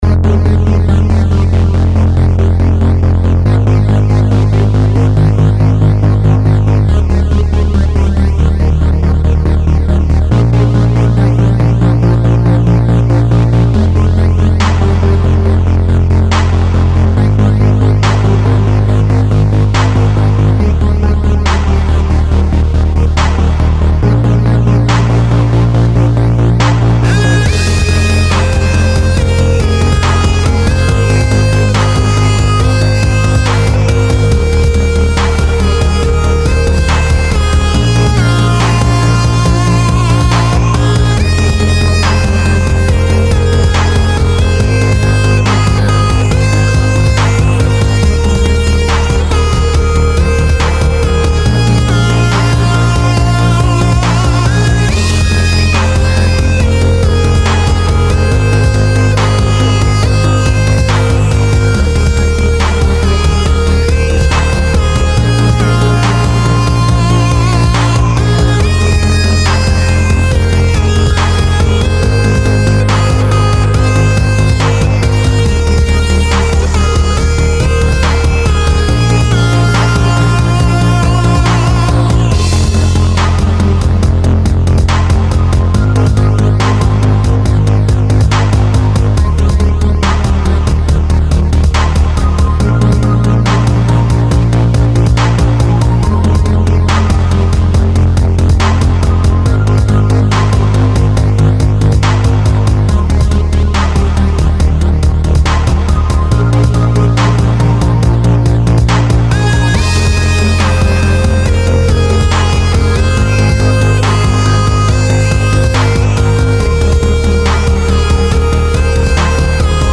I will regularly post new electronica tracks I've started working on as well as links to recent photographs I've taken.
I was really pleased with how well I managed to tweak the bass instrument, and I think the beat came out quite well.